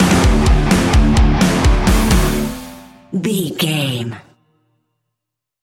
Ionian/Major
hard rock
heavy metal
instrumentals